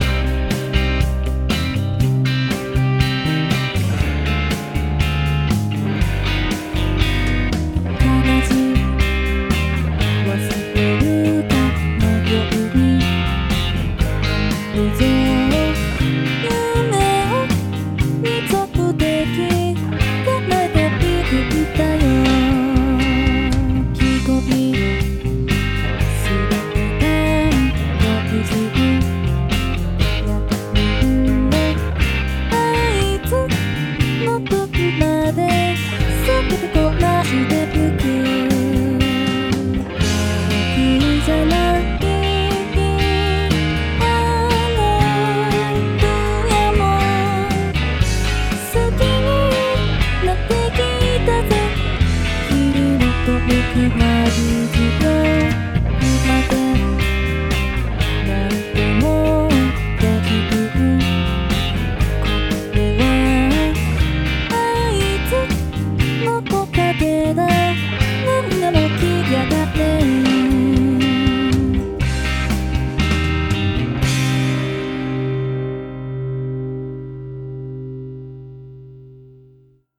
歌(104曲)